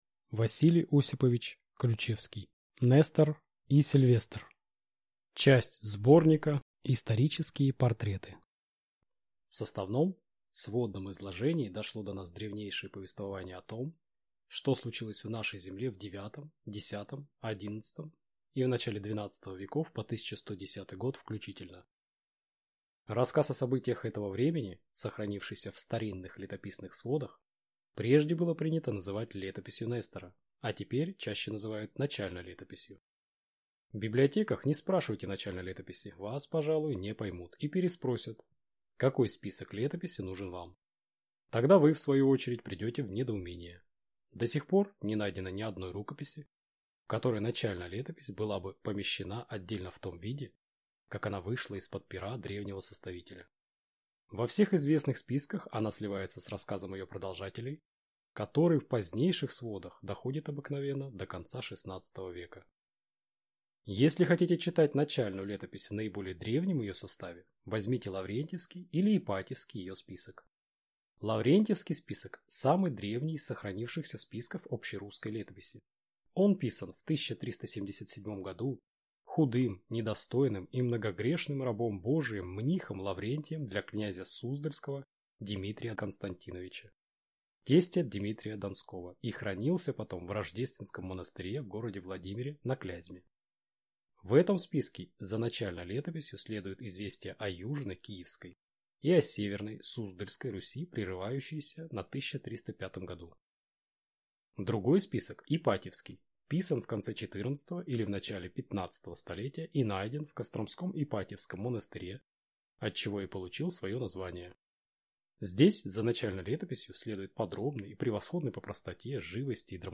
Аудиокнига Нестор и Сильвестр | Библиотека аудиокниг